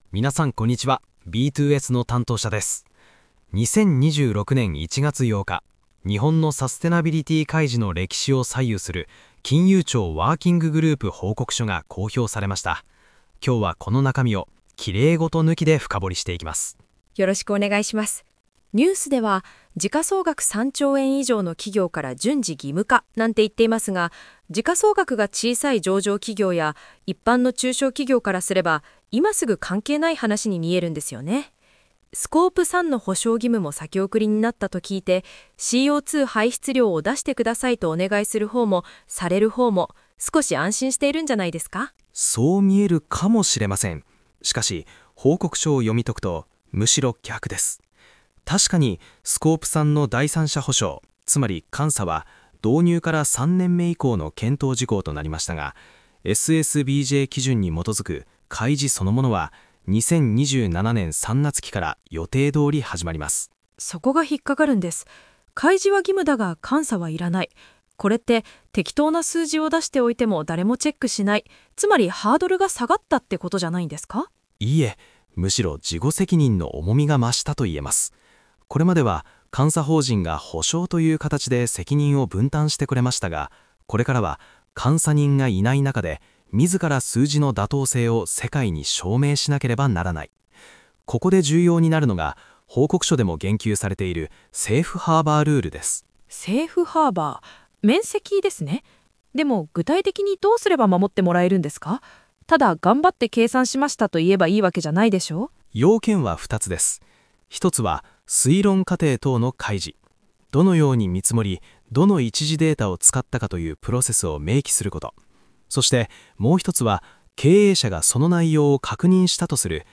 【音声解説】6分でわかる「免責（セーフハーバー）」の勝ち取り方
サプライヤーからのデータ収集に基づく、Scope3算定の根拠の重要性をご理解頂けます。経営者の法的リスクをどう回避するか？戦略参謀が対談で紐解きます。